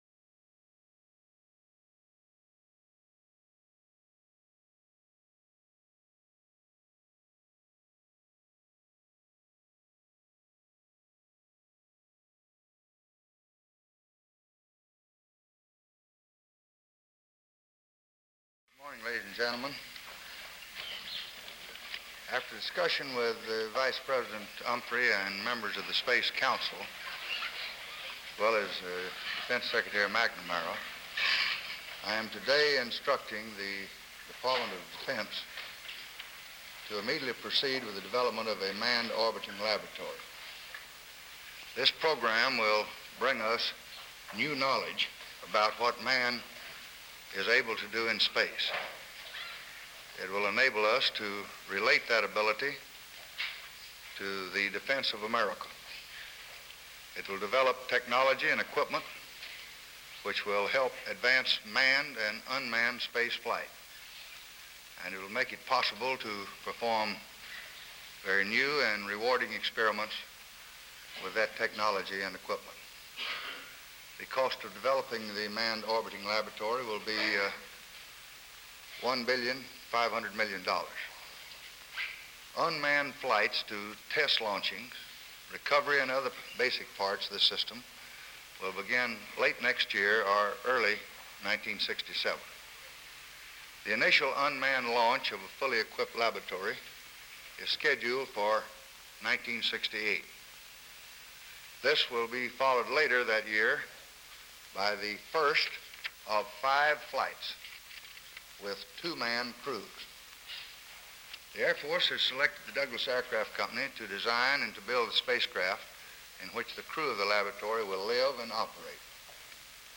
August 25, 1965: Press Conference at the White House | Miller Center